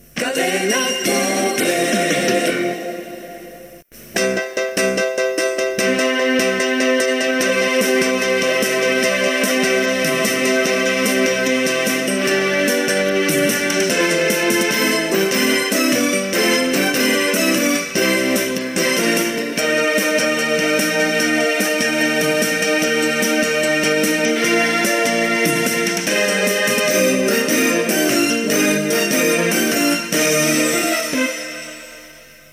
Indicatiu i sintonia